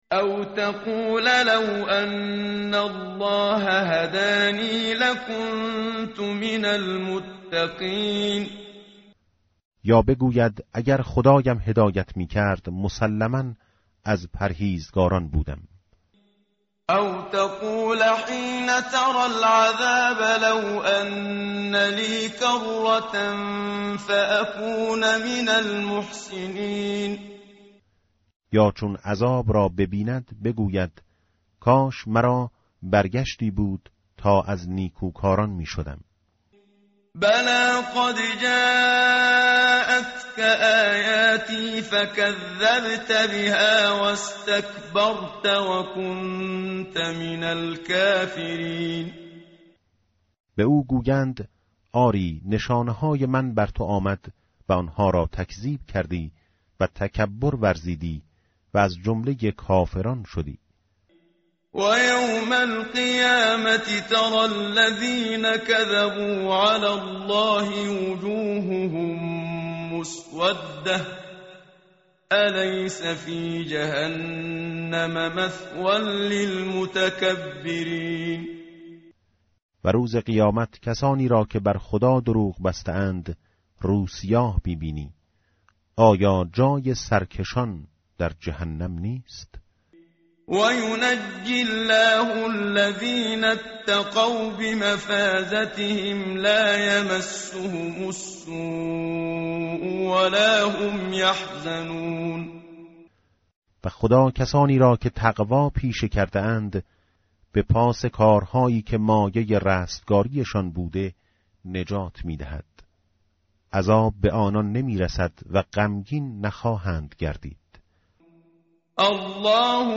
tartil_menshavi va tarjome_Page_465.mp3